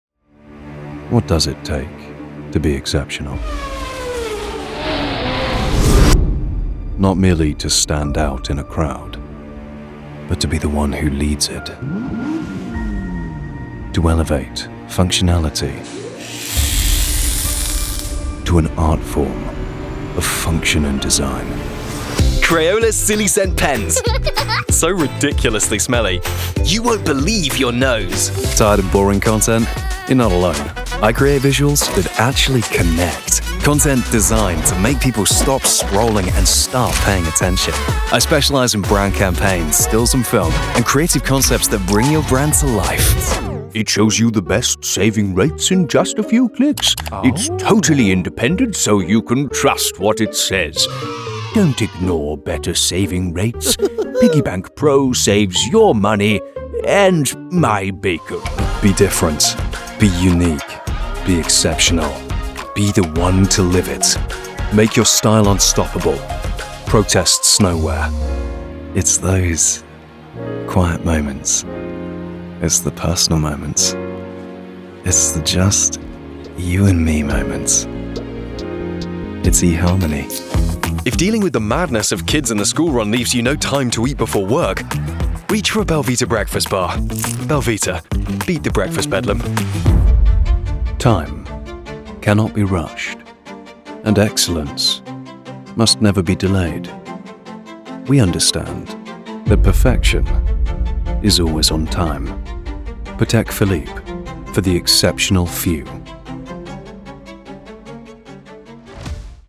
Commerciale, Naturelle, Amicale, Chaude, Polyvalente
Commercial